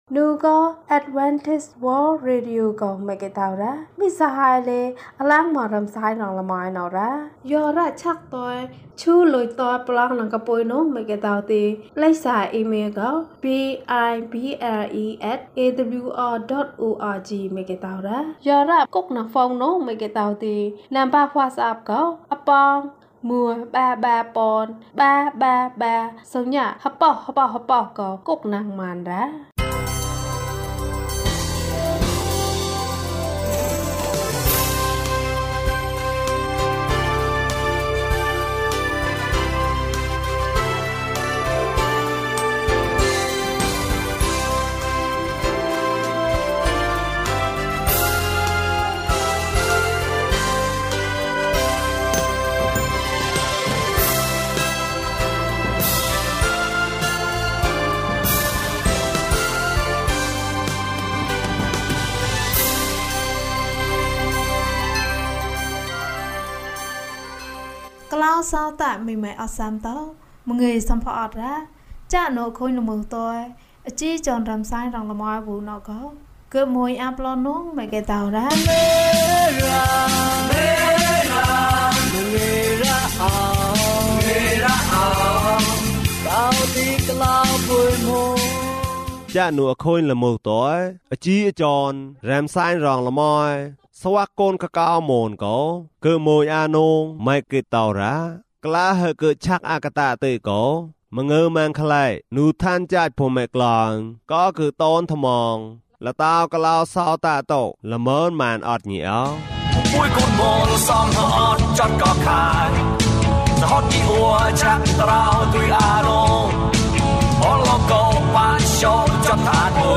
ခရစ်တော်ထံသို့ ခြေလှမ်း။ ၂၀ ကျန်းမာခြင်းအကြောင်းအရာ။ ဓမ္မသီချင်း။ တရားဒေသနာ။